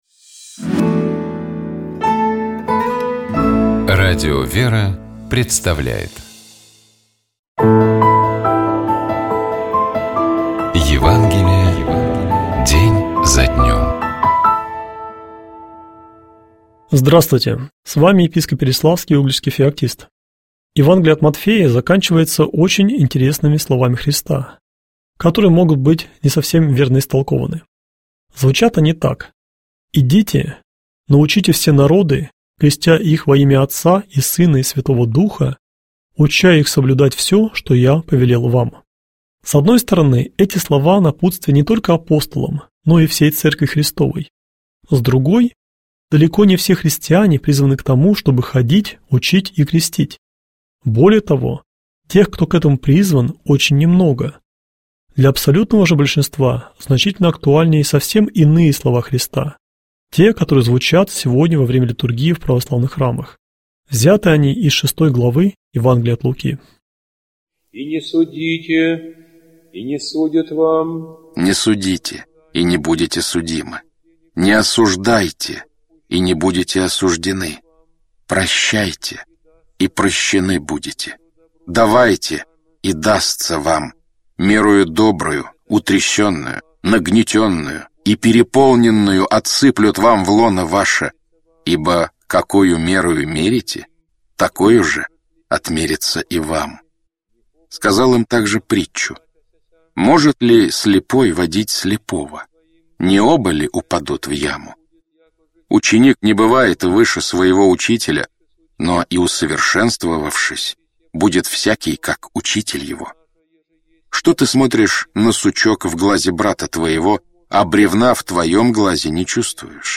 Читает и комментирует
епископ Переславский и Угличский Феоктист